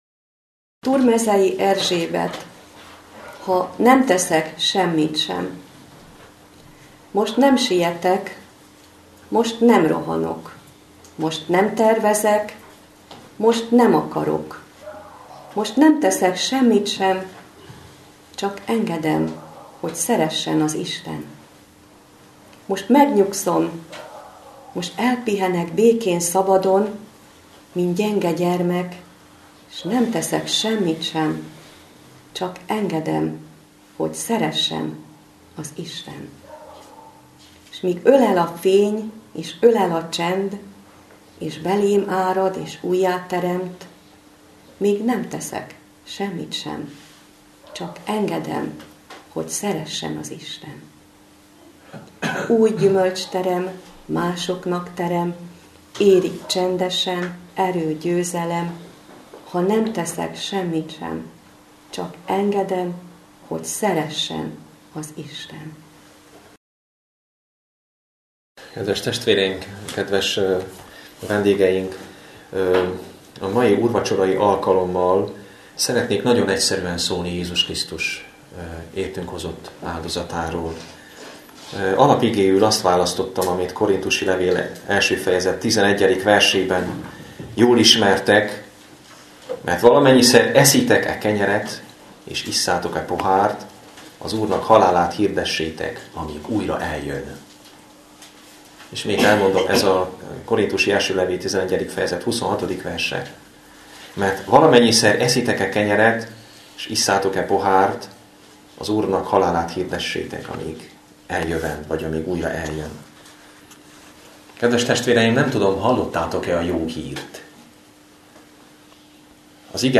Szombati igehirdetés